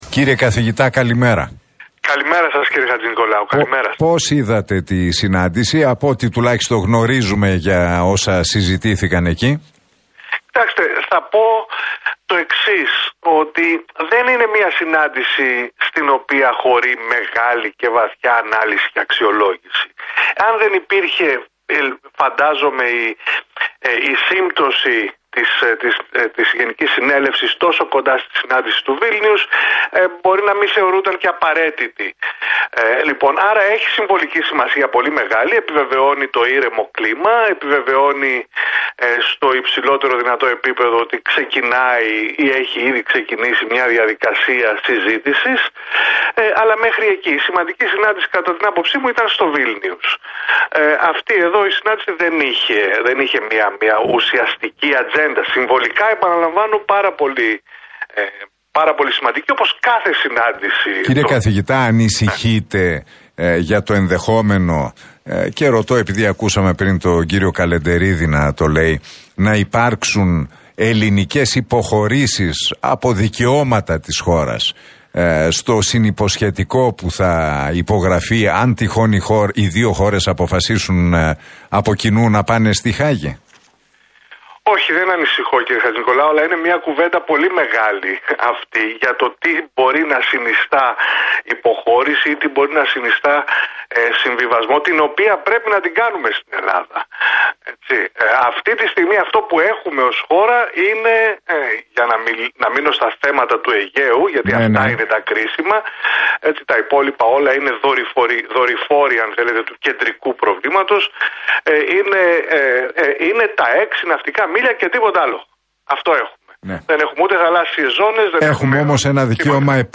Ρετζέπ Ταγίπ Ερντογάν έκαναν μιλώντας στον Realfm 97,8 και την εκπομπή του Νίκου Χατζηνικολάου ο γεωπολιτικός αναλυτής και συγγραφέας